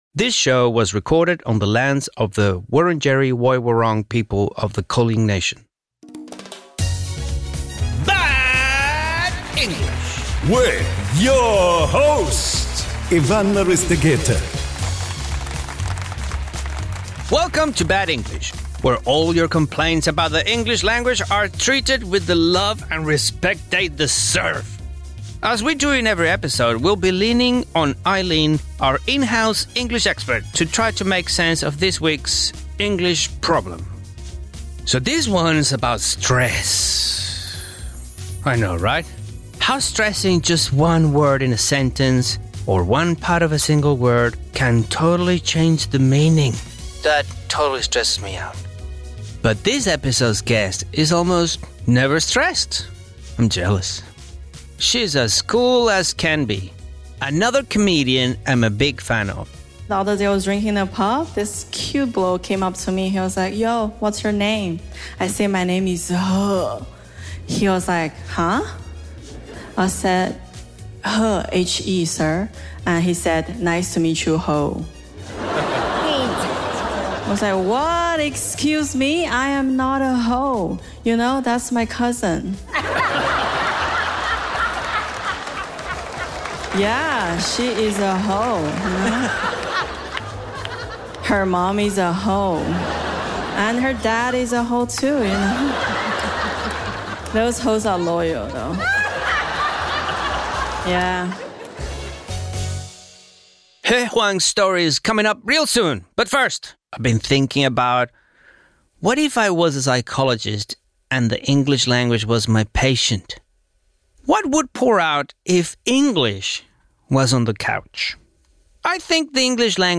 Bad English is a comedy podcast from SBS Audio about the lows of learning the world's most widely spoken and studied language.